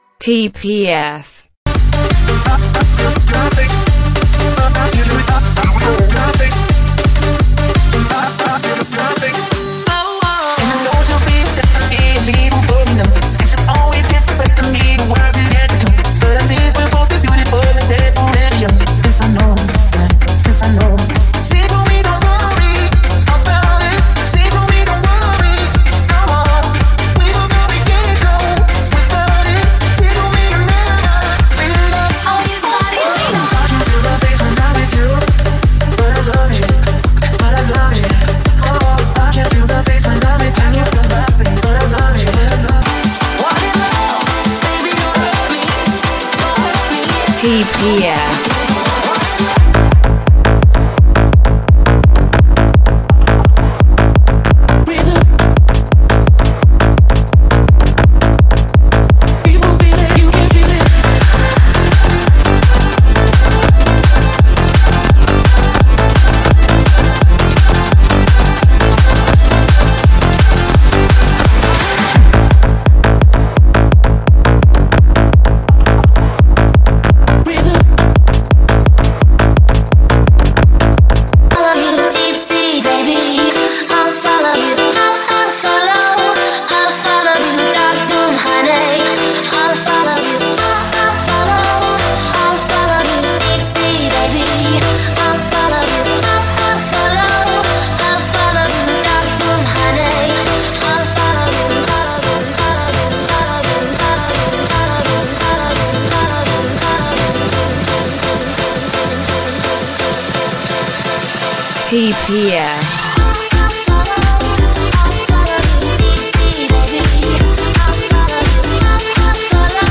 (32 count phrased) 170 BPM
dance, cardio, aerobics, Fitness…
Tempo:      170 BPM